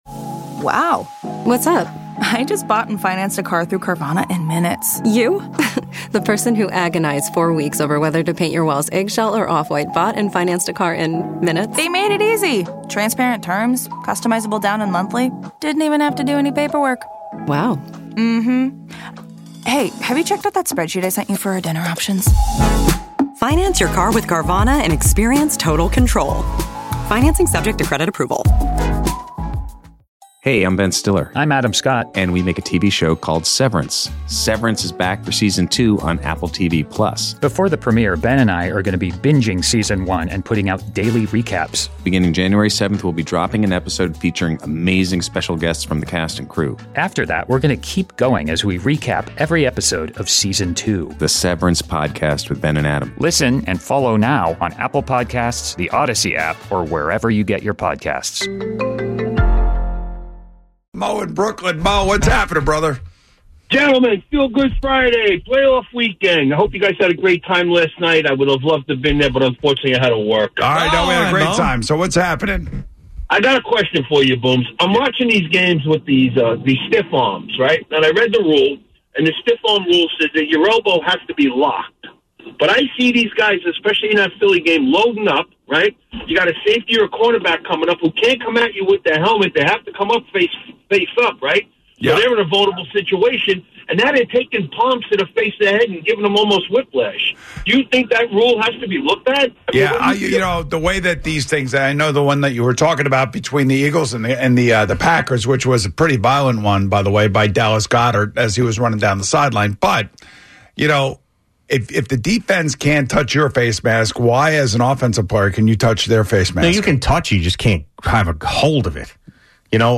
We talked about the best stiff arm guys of all time. A caller is happy the Giants kept both the GM and Head Coach.